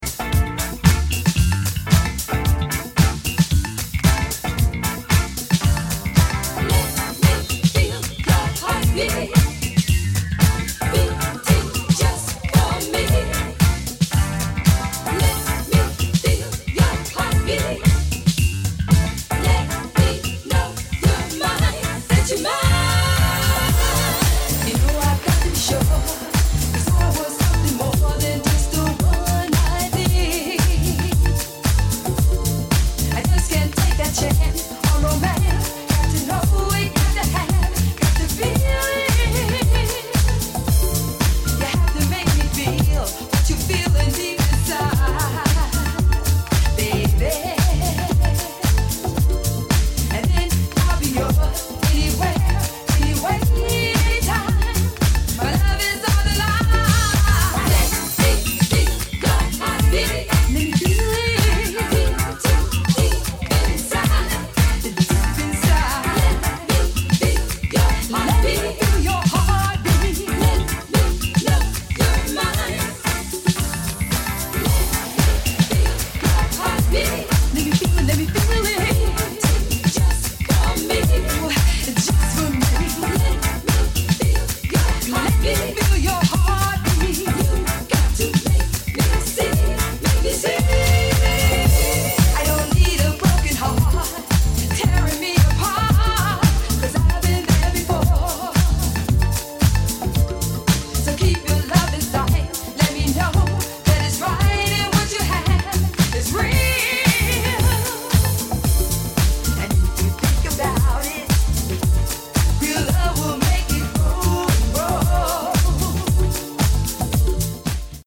Disco / Balearic Edit